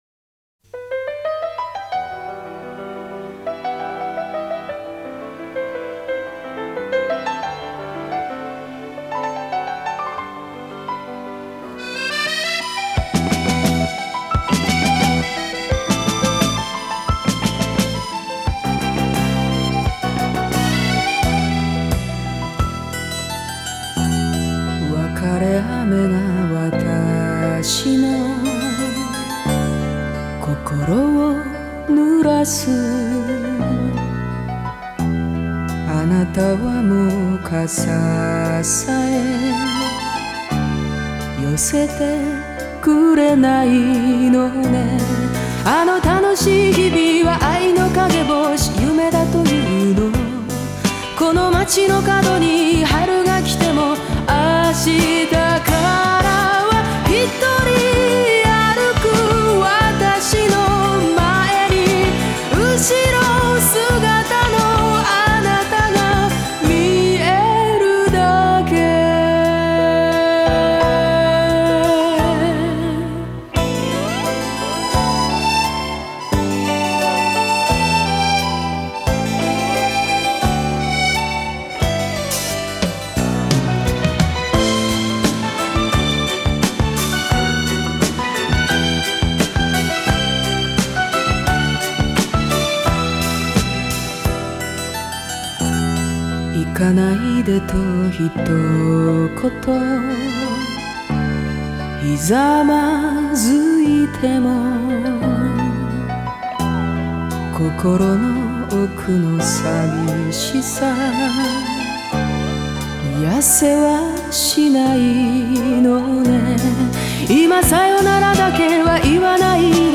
ジャンル： Japanese Pop